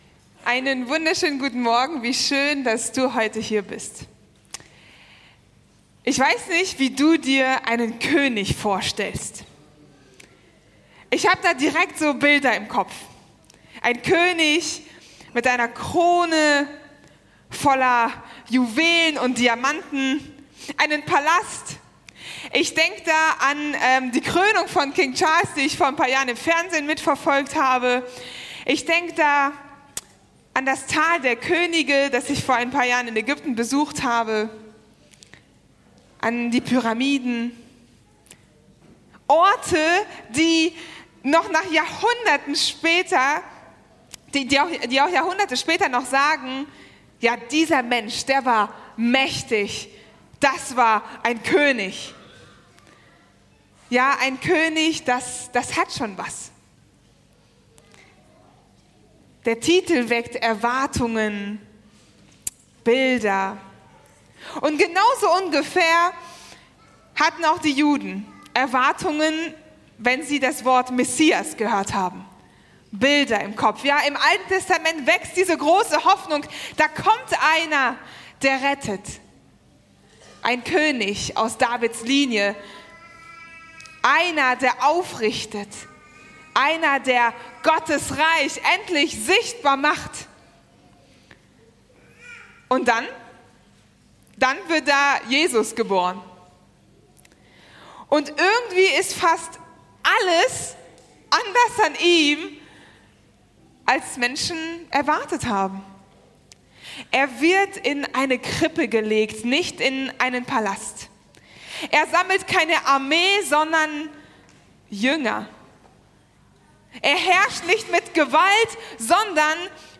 Mitschnitt vom 28.03.2026 zum Thema „Ueberrascht vom Kreuz"